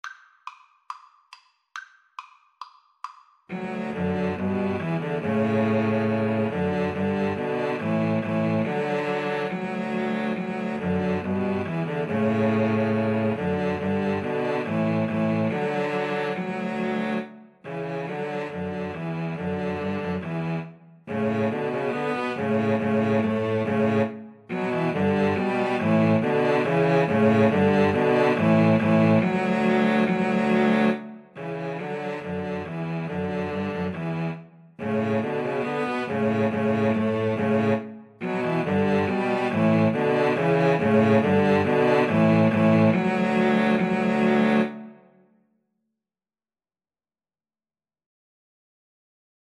Cello Trio  (View more Easy Cello Trio Music)